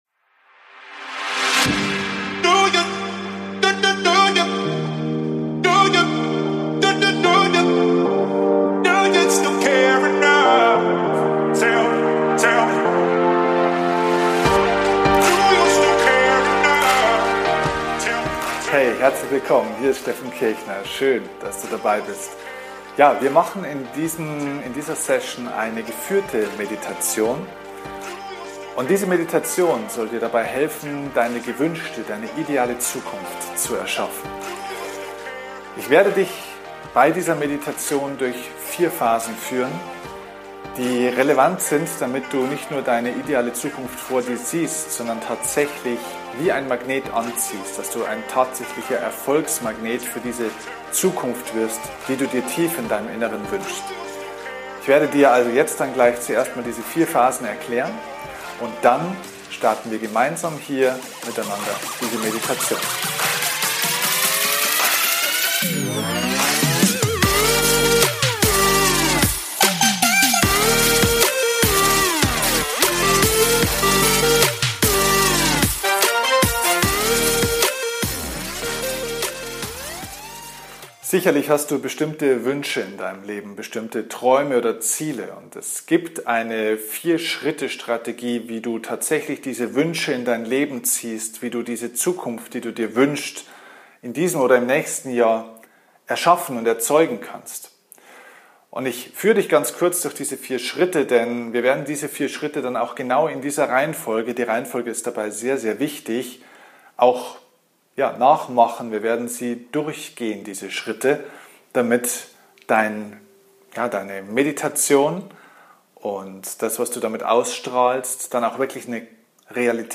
#364 Geführte Meditation – Erschaffe Deine ideale Zukunft | Meditation | innere Ruhe | Optimismus Heute bekommst Du von mir eine geführte Meditation. Passend zum Jahreswechsel geht es dabei, Diene ideale Zukunft zu erschaffen.